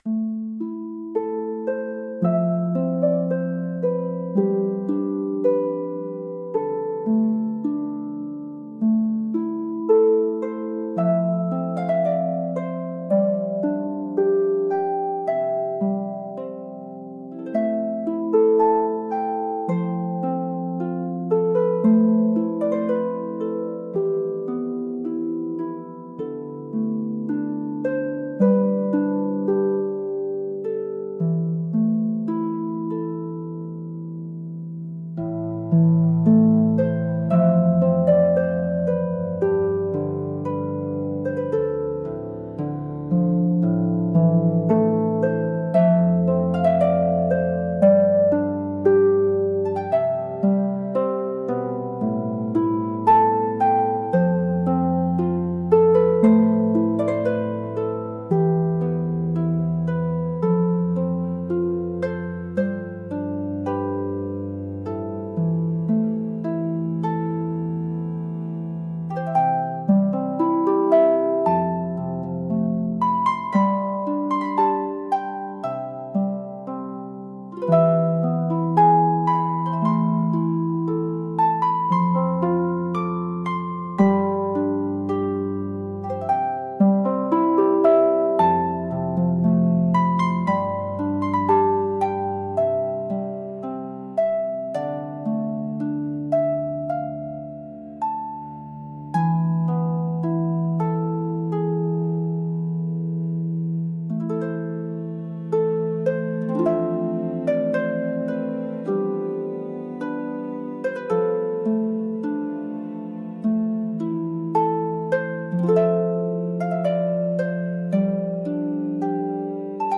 フリーBGM 🎶 静かで幻想的な図書館の中、眠っていた夢の記憶が本のページからそっとあふれ出すようなBGMです。